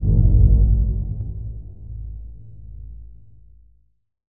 Low End 20.wav